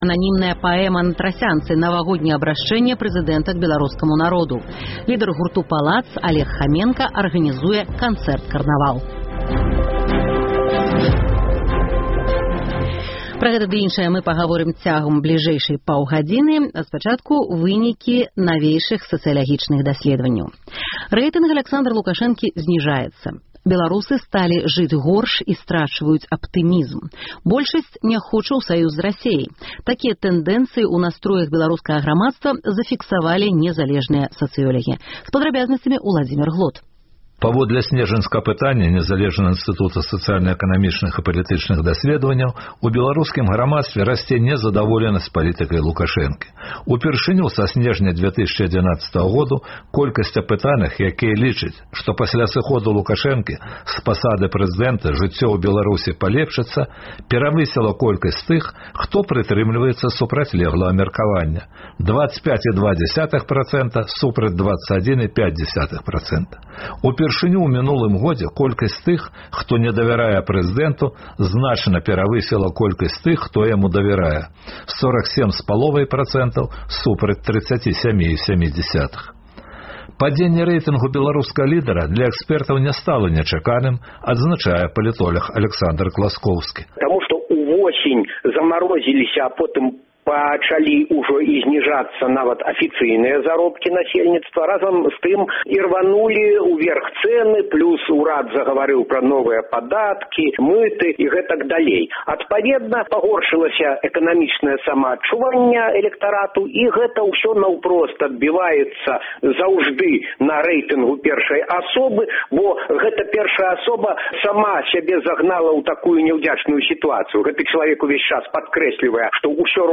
Быў сярод іх і карэспандэнт Свабоды. Вы пачуеце ягоныя ўражаньні ад навагодняга Майдану.